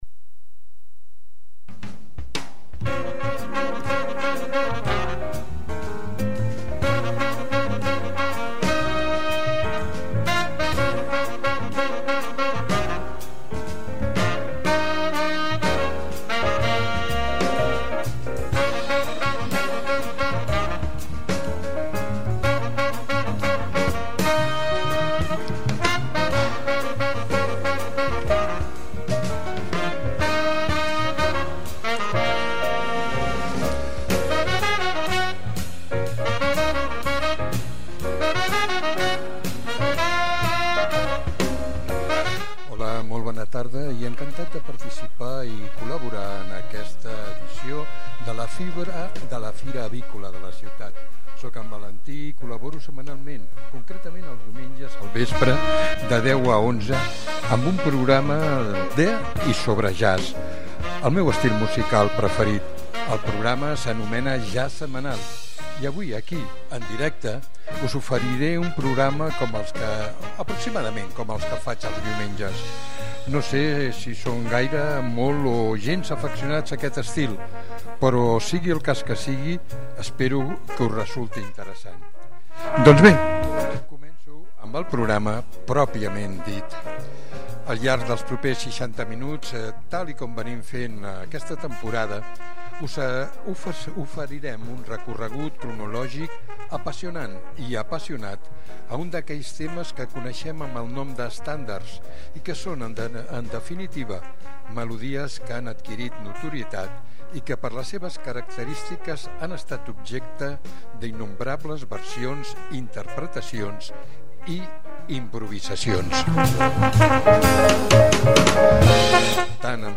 Un repàs apassionat i apassionant als millors standards de la nostra música.